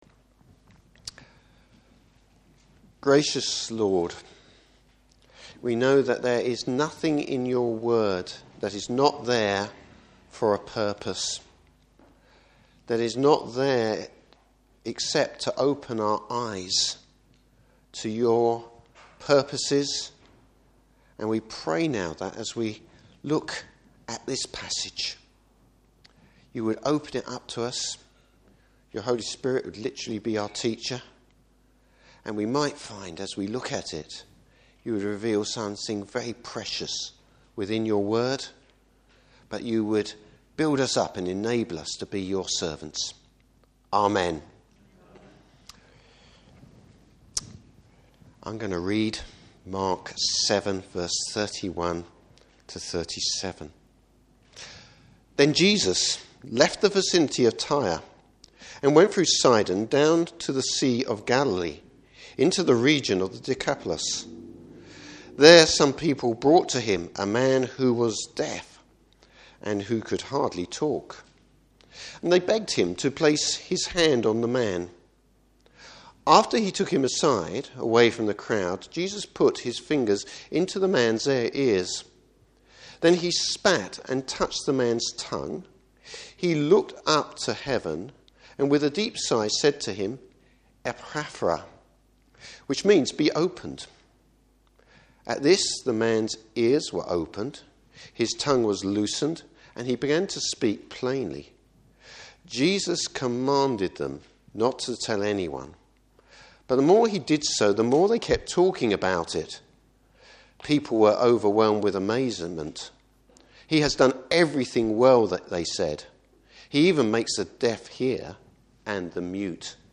Service Type: Morning Service A demonstration of authority. Topics: Jesus revealing His purpose.